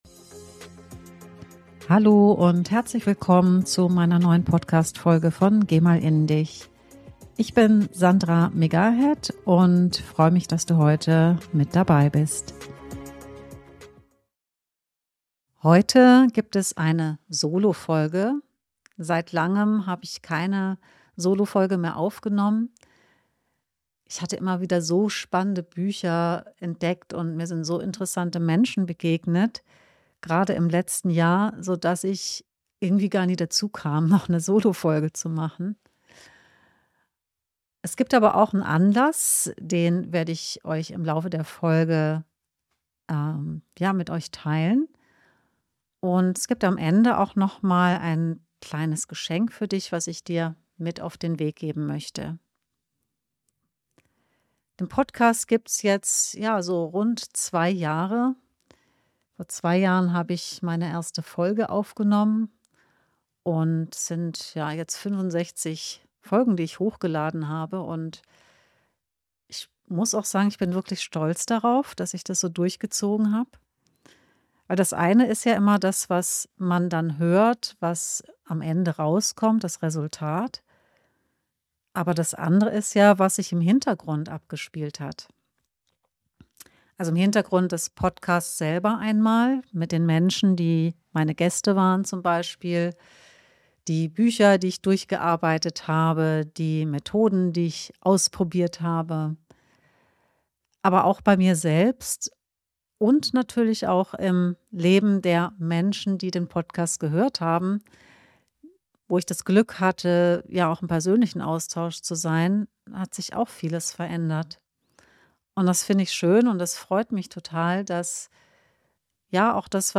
In dieser Solofolge blicke ich auf 2 Jahre und 65 Folgen zurück. Ich teile Highlights, Learnings und besondere Momente mit Menschen und Impulse, die mich in dieser Zeit besonders geprägt haben.